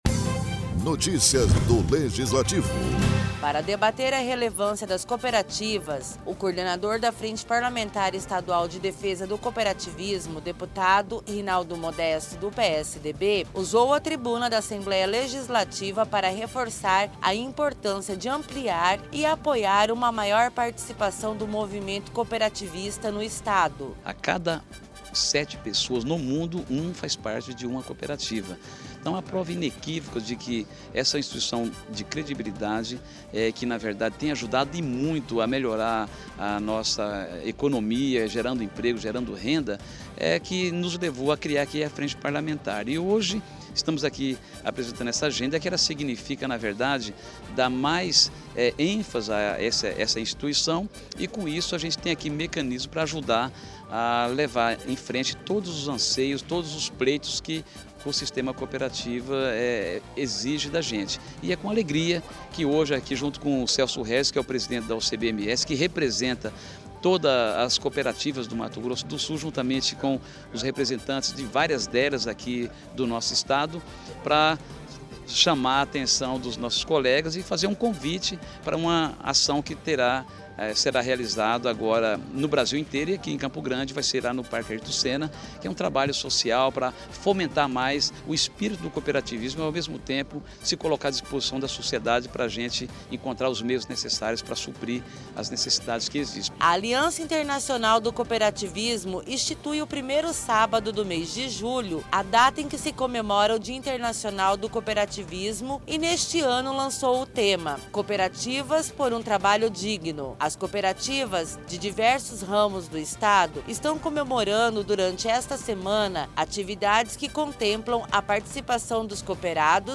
O deputado estadual Professor Rinaldo, do PSDB usou à tribuna na Assembleia para debater a importância do sistema de cooperativismo.